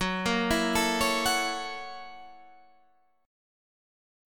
F# 7th Sharp 9th